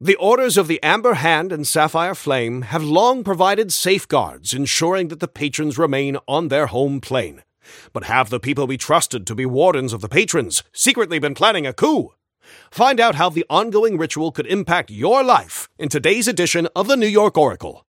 Newscaster_headline_80.mp3